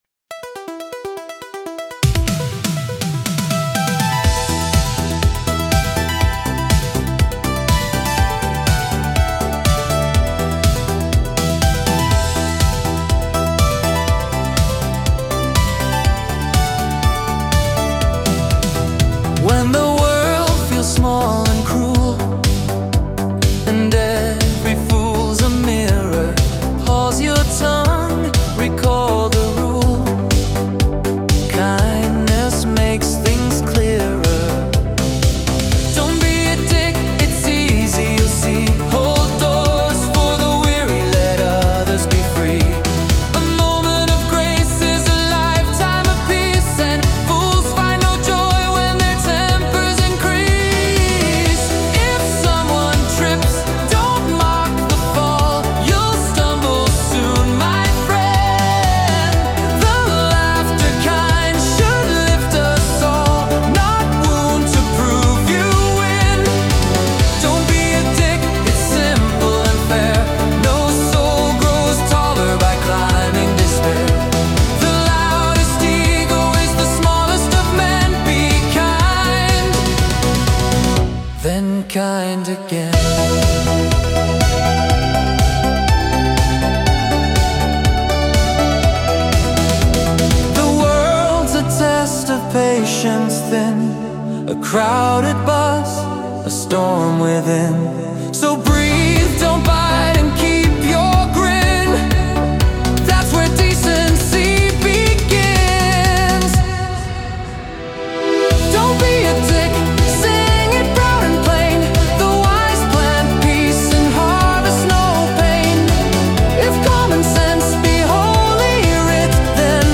General Hymns
Pop